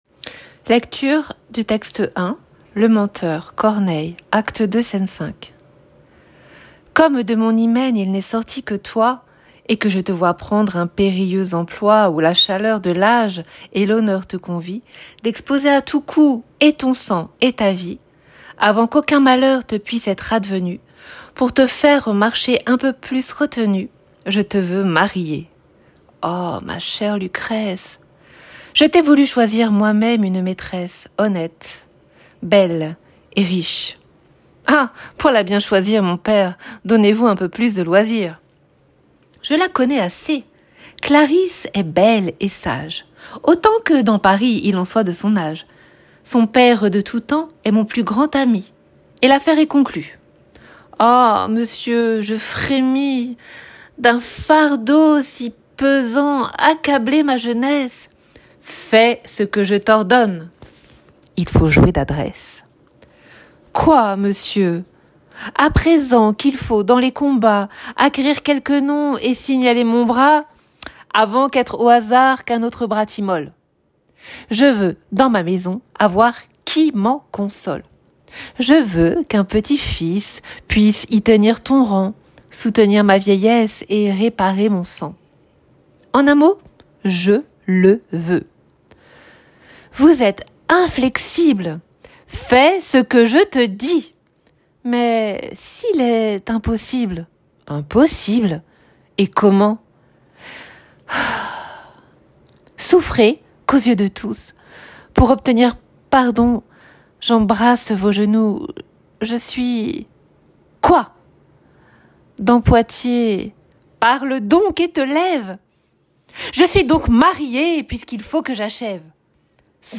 En bas de cette page, vous pouvez écouter la lecture du texte de Corneille.
lecture_texte_1.m4a